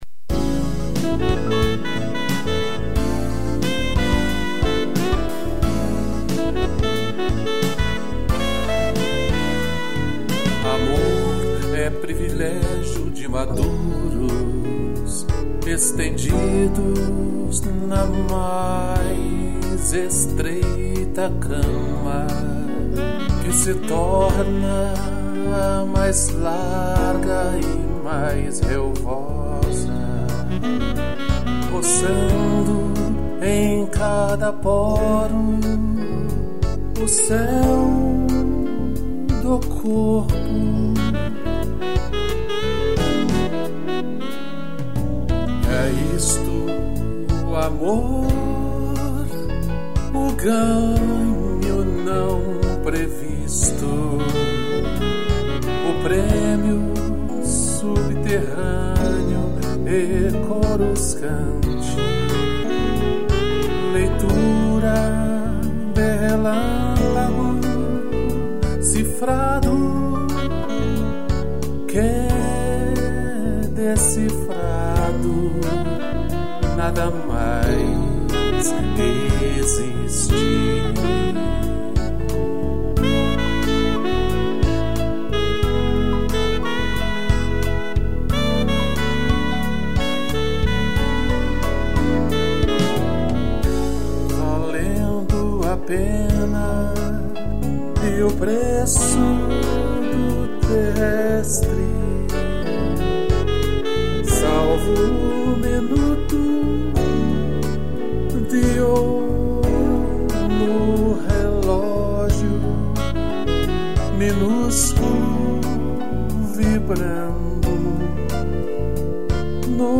Voz
piano e sax